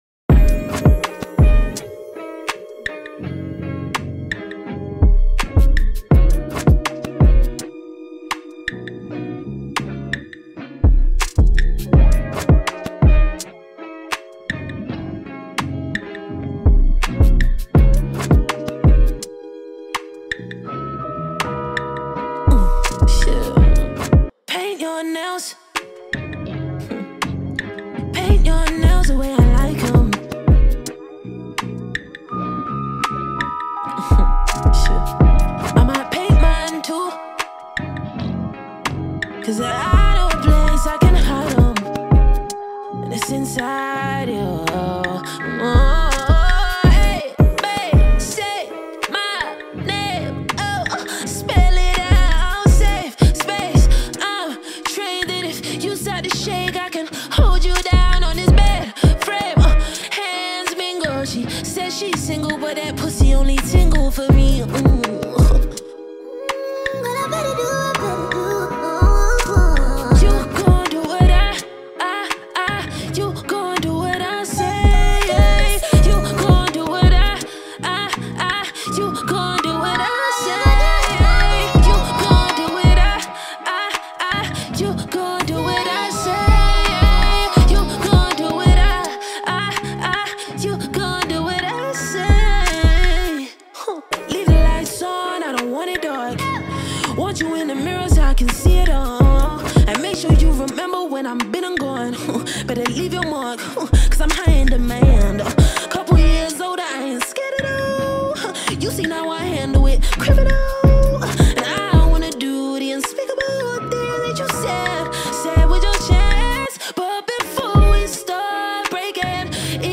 blending R&B, pop, and rock influences
signature soulful vocals and emotionally charged lyrics